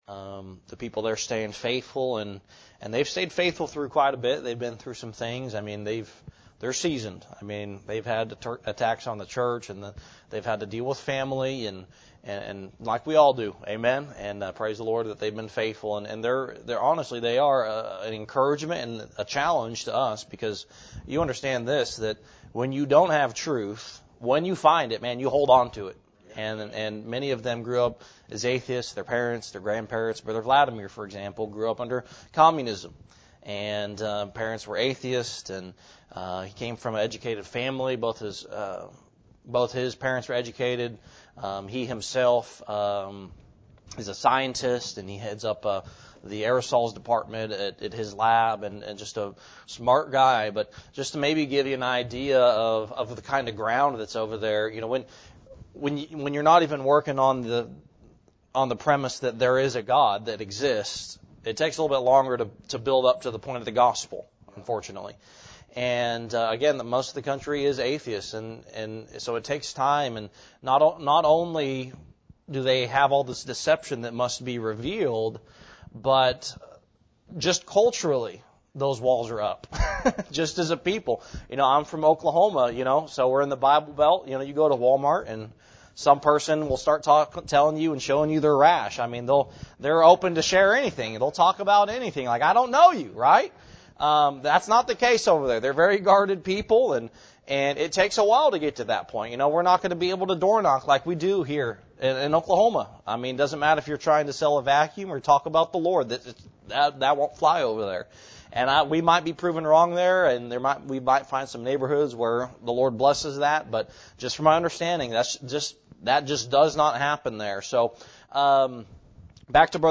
Are You Salty – Cornerstone Baptist Church | McAlester, OK